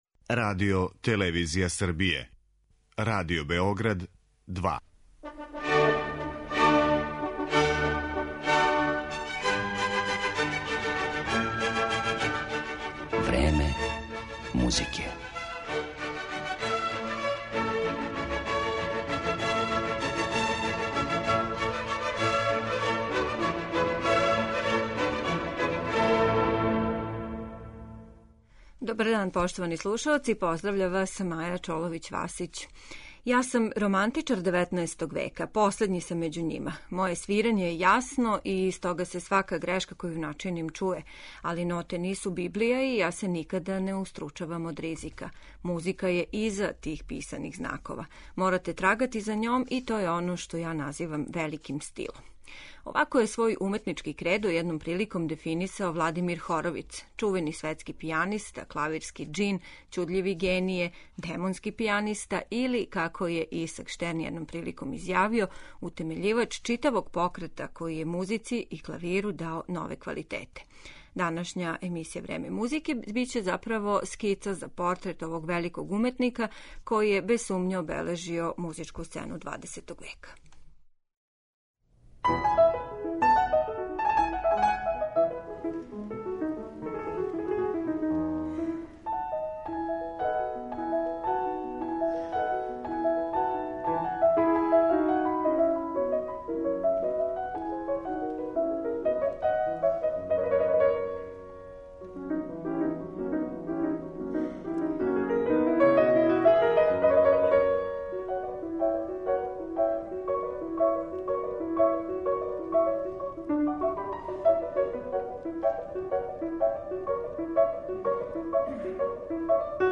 Ми ћемо га представити фрагметнима из опуса Скарлатија, Шопена, Листа и Рахмањинова.